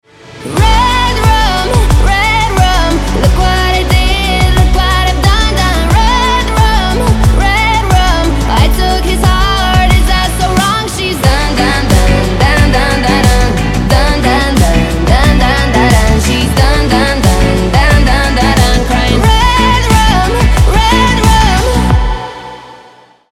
• Качество: 320, Stereo
женский голос
Dance Pop